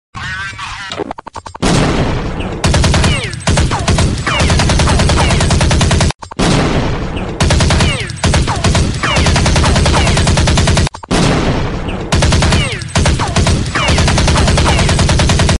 Nhạc chuông tiếng súng Đột sound effects free download